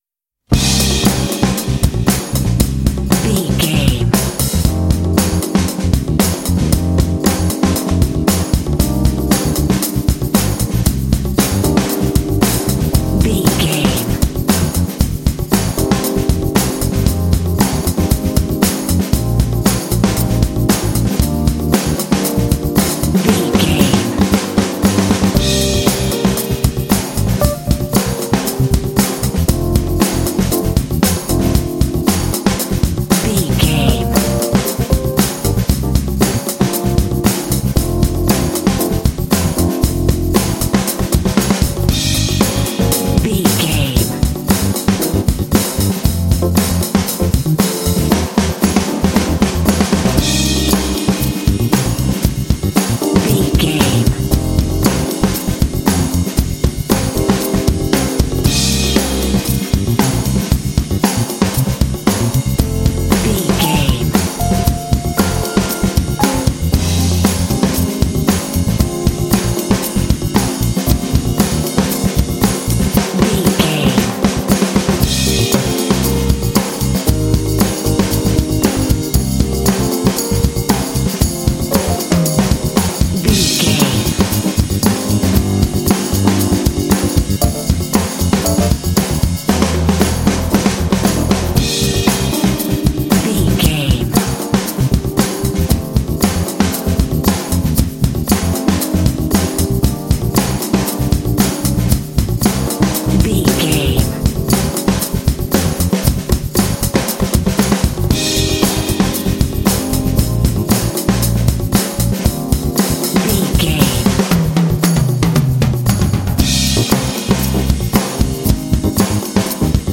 This funky track is ideal for kids and sports games.
Uplifting
Ionian/Major
E♭
funky
groovy
confident
piano
drums
percussion
bass guitar
electric piano
Funk
big band
jazz